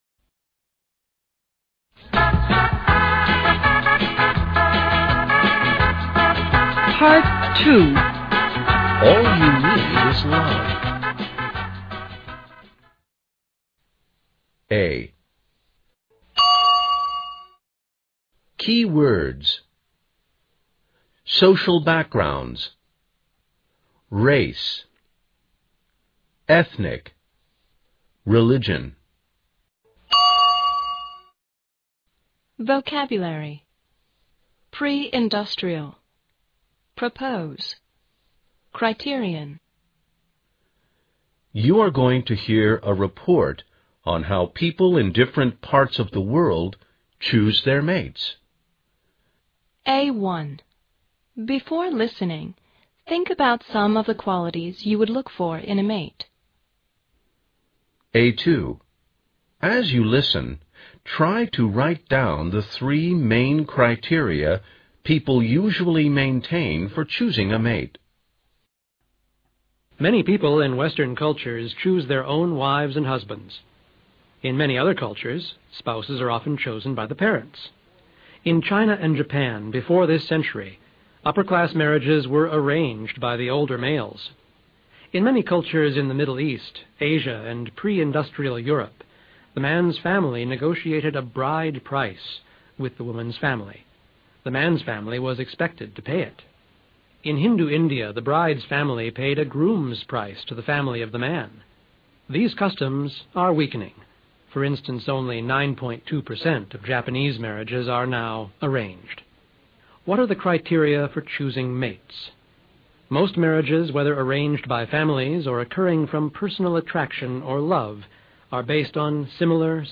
You are going to hear a report on how people in different parts of the world choose their mates.
Listen to a dialogue that discusses personal and family relationships, complete the following main id